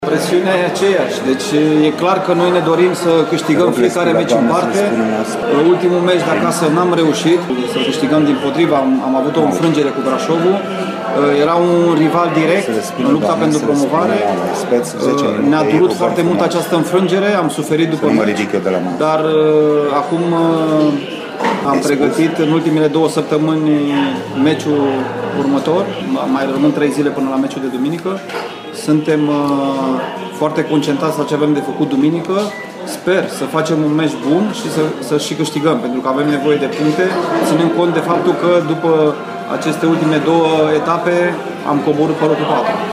Calitatea slabă a fișierului audio este cauzată de condițiile tehnice asigurate pentru conferințele de presă ale trupei arădene. În ultima perioadă, întâlnirile cu jurnaliștii au fost organizate în cafenele din Arad, fără a fi restriciționat accesul clienților.